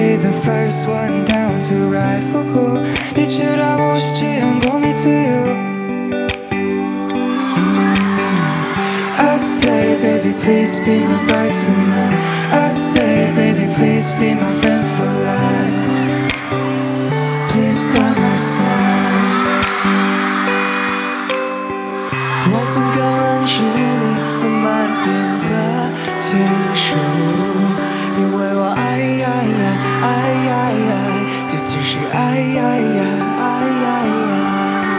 Жанр: Поп
# Mandopop